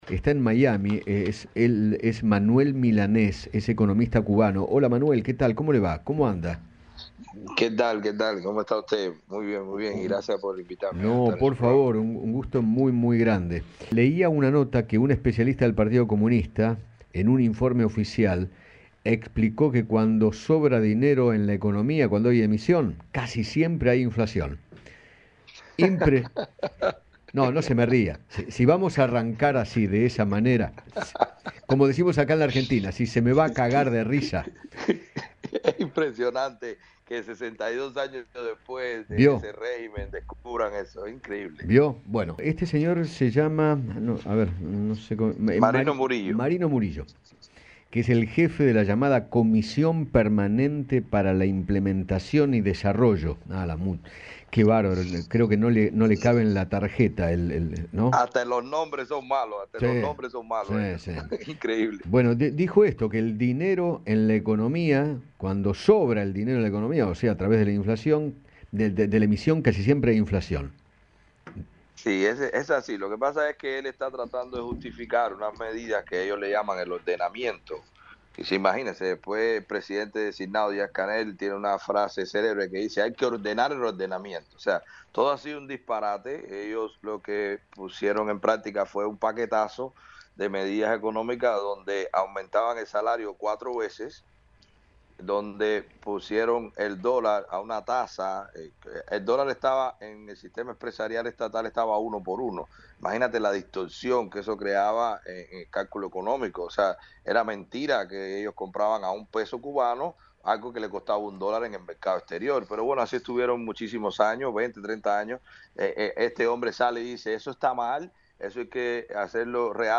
habló con Eduardo Feinmann acerca del presente financiero y social de Cuba, centrado en el control de precios y la emisión monetaria.